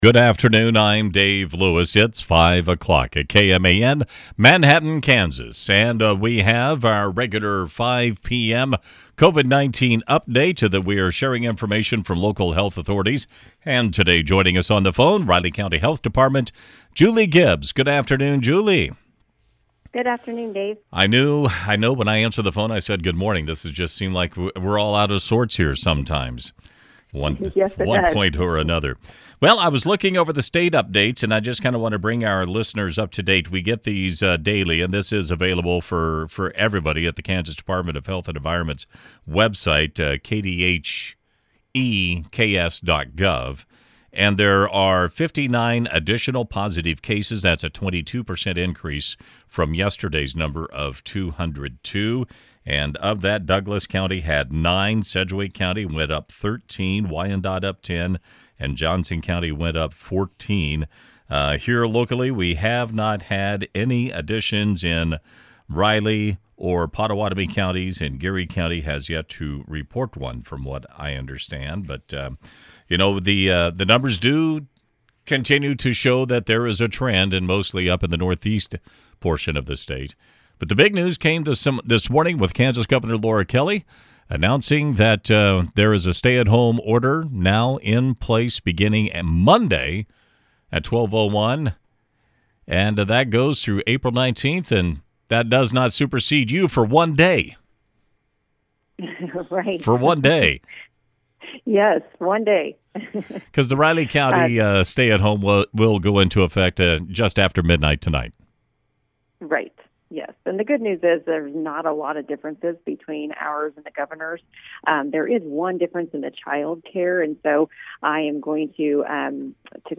RCHD Director Julie Gibbs joined News Radio KMAN during the 5 p.m. hour for our daily COVID-19 update.